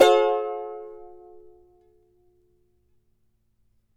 CHAR G MN  D.wav